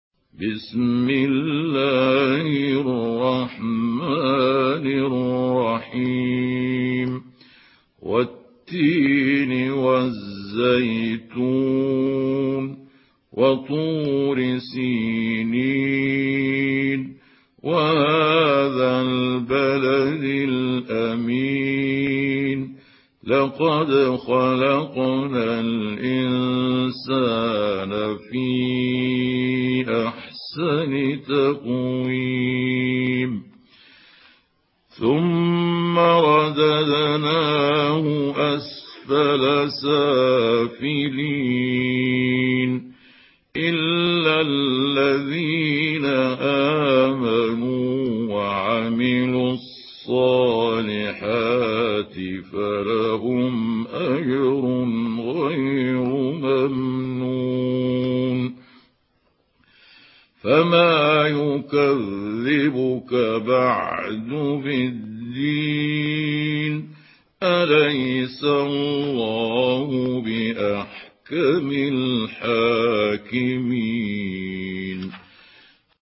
سورة التين MP3 بصوت محمود عبد الحكم برواية حفص
مرتل